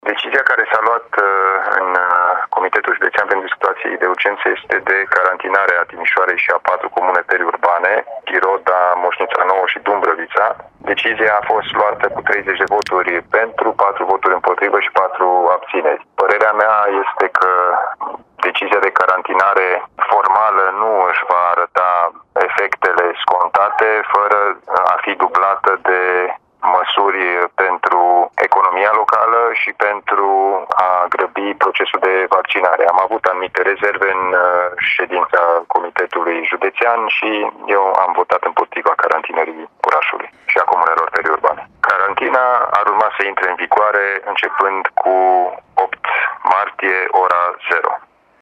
alin-nica-declaratie.mp3